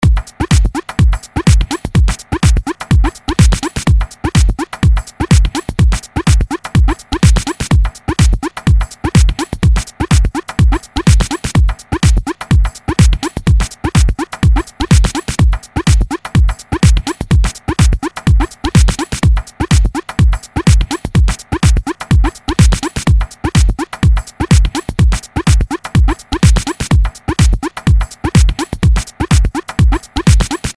LP 135 – DRUM LOOP – EDM – 125BPM